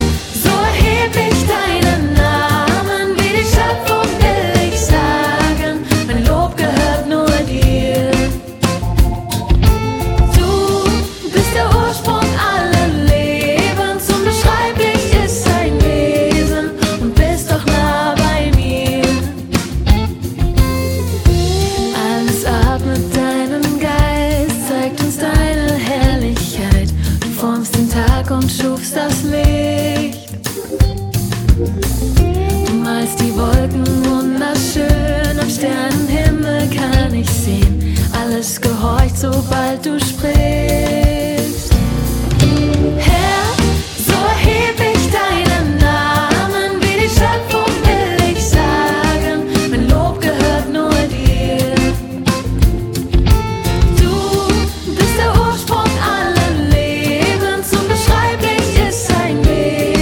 Lobpreis
Gesang